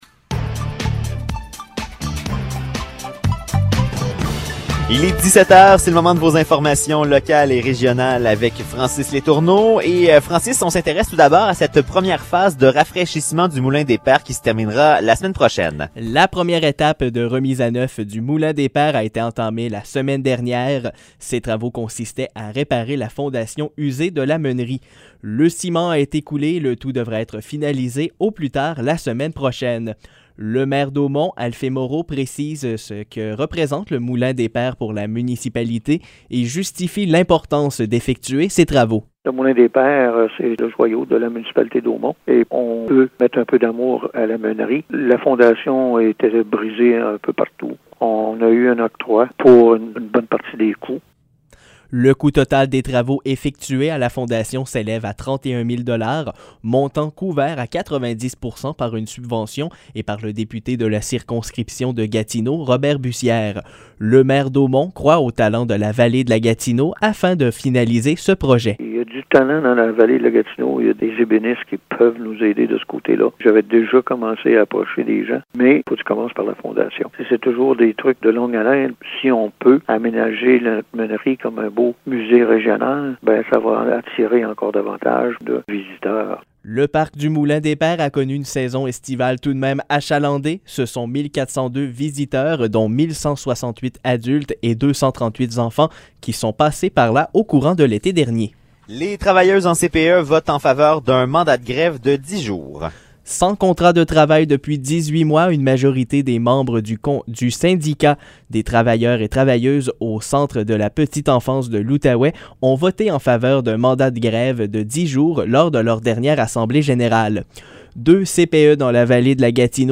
Nouvelles locales - 15 septembre 2021 - 17 h